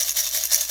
Shaker 08.wav